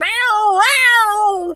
pgs/Assets/Audio/Animal_Impersonations/cat_scream_09.wav at master
cat_scream_09.wav